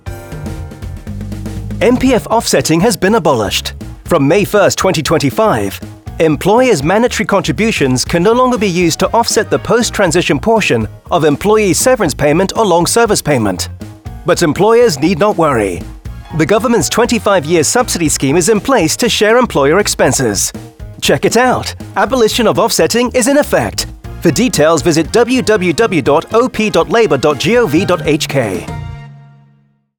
Radio announcement on abolition of MPF offsetting arrangement